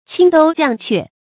發音讀音